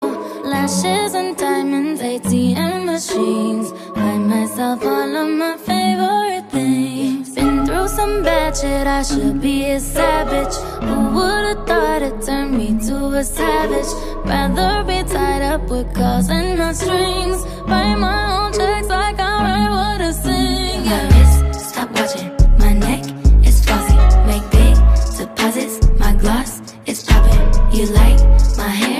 Category: Message Ringtones